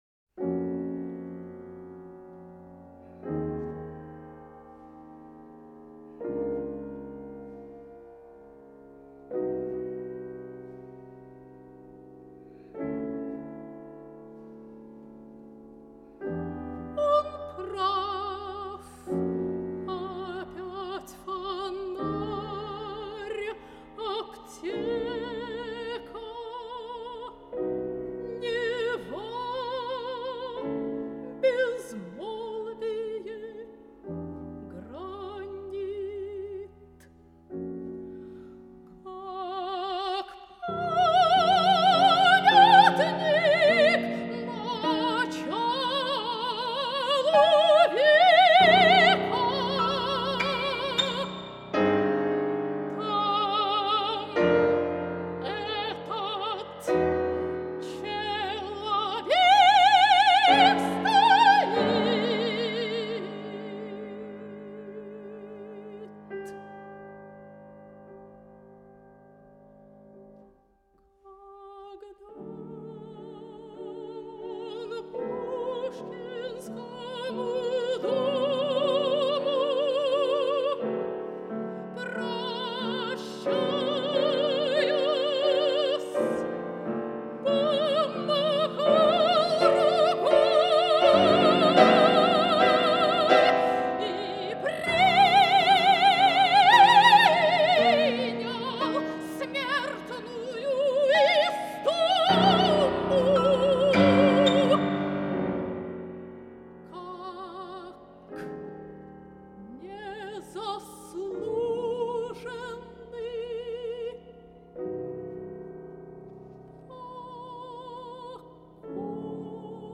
Романсы на стихи русских поэтов